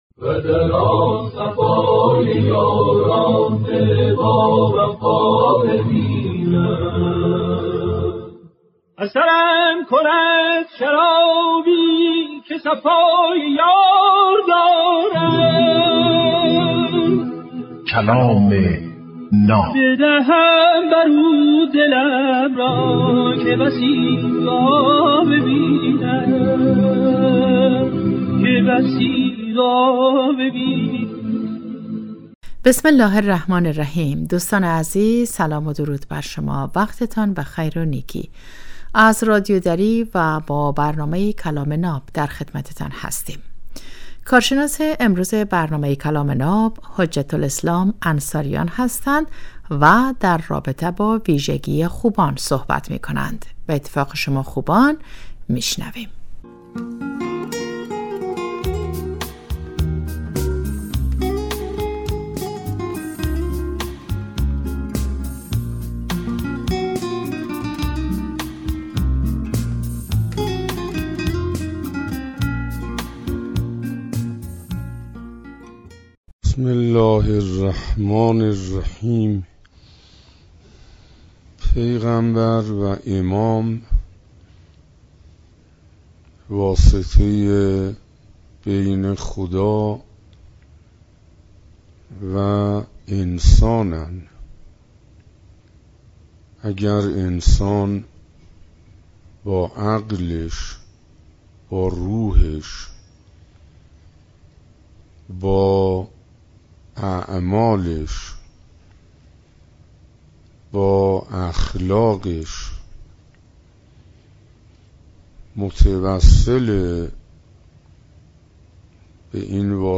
کلام ناب برنامه ای از سخنان بزرگان است که هر روز ساعت 7:35 عصر به وقت افغانستان به مدت 10دقیقه پخش می شود.